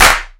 Clap19.wav